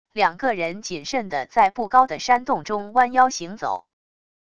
两个人谨慎地在不高的山洞中弯腰行走wav音频